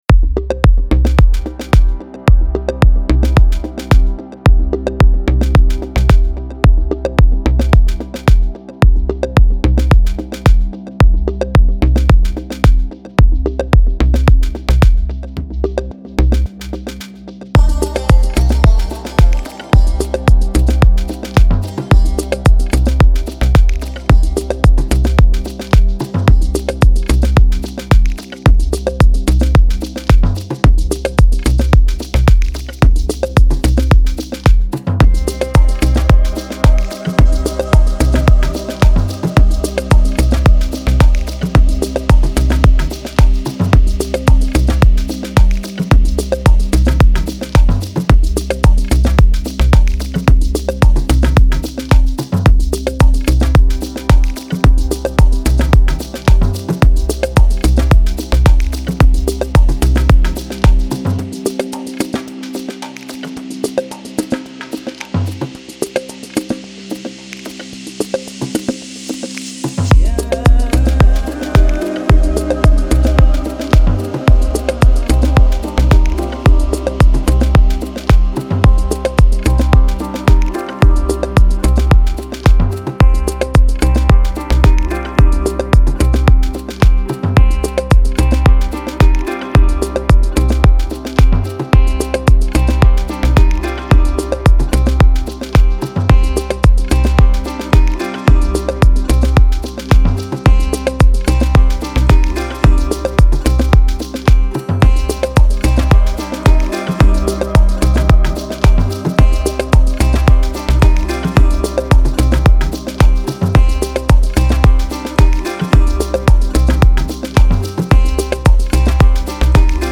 امید‌بخش بومی و محلی رقص موسیقی بی کلام